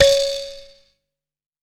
Index of /90_sSampleCDs/AKAI S6000 CD-ROM - Volume 5/Africa/KALIMBA